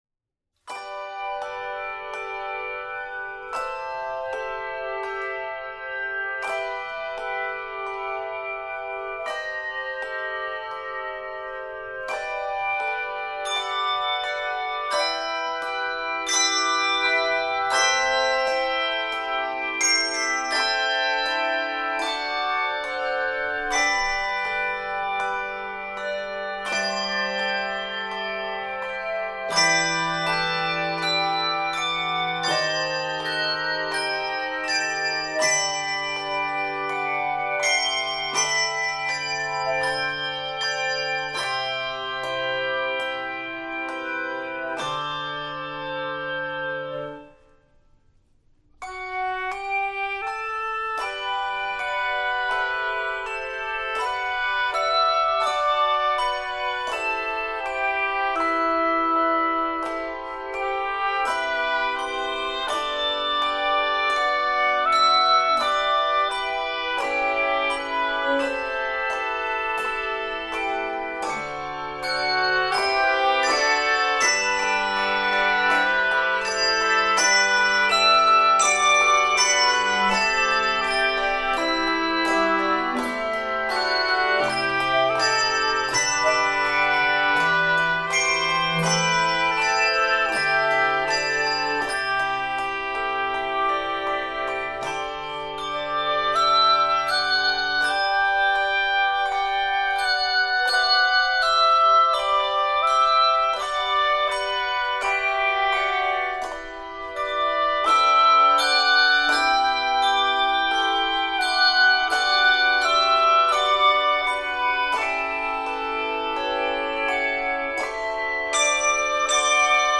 The heartwarming Irish tune
is given a gentle, traditional treatment
Key of G Major.